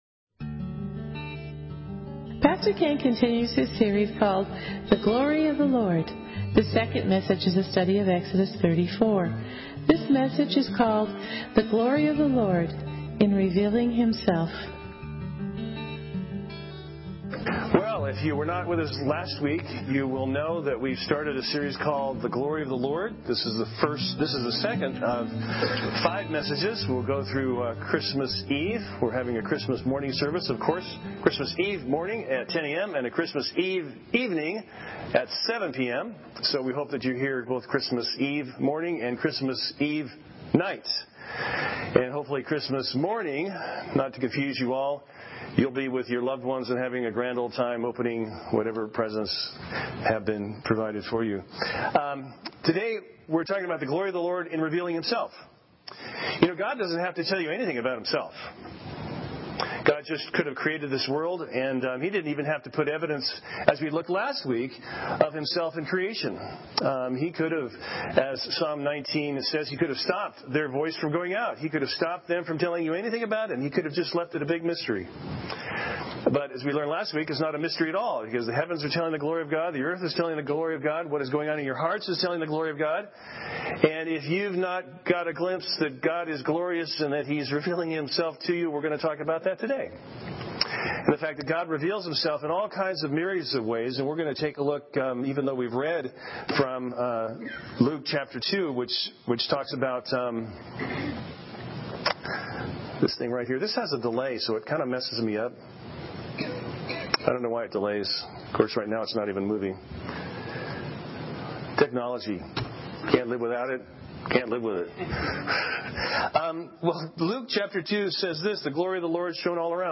Sermons Archive - Page 26 of 34 - Christ Our Savior UMC, Quartz Hill